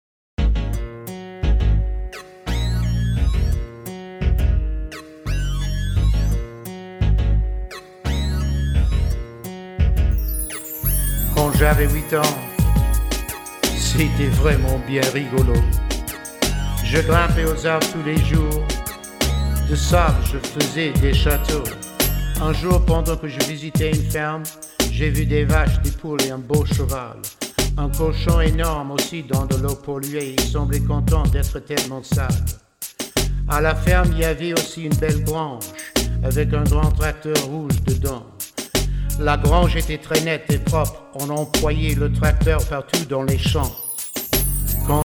French Language Raps